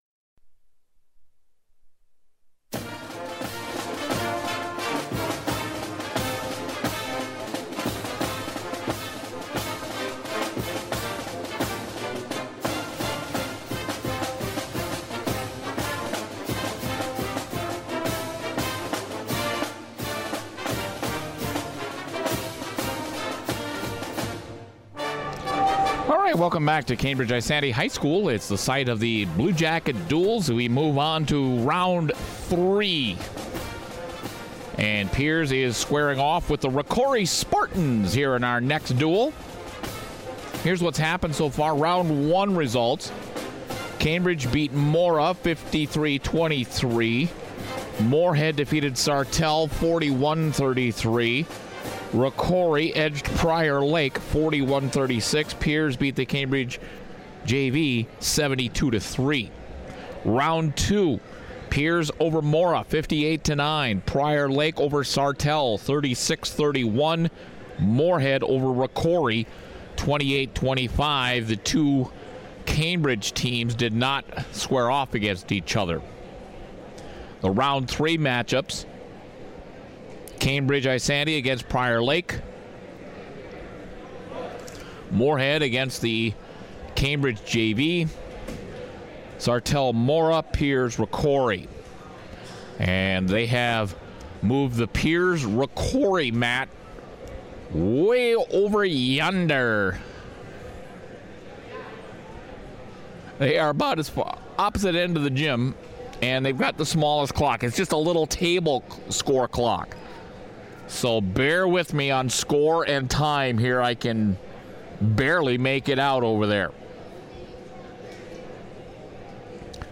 The Pioneers overcome a 22-10 lead for the Spartans after 8 matches by scoring the final 30 points to win 40-22 in Round 3 at the Bluejacket Duals in Cambridge.